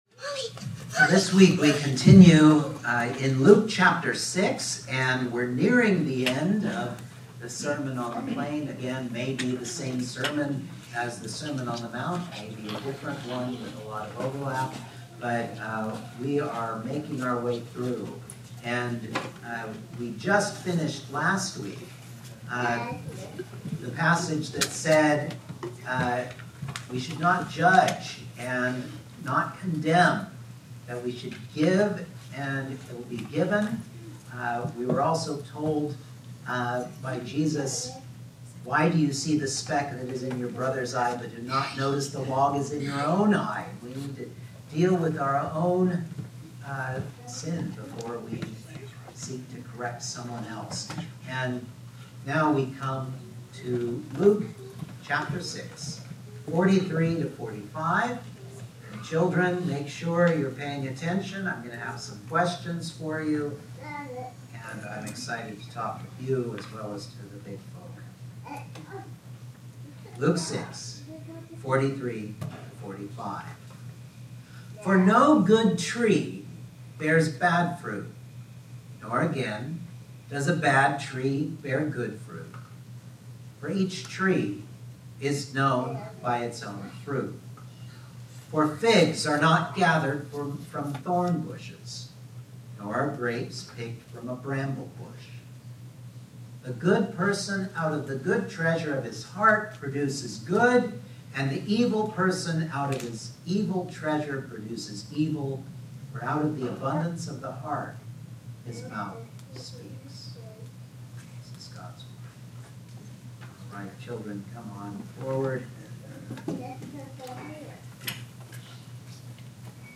Luke 6:43-45 Service Type: Sunday Morning Outline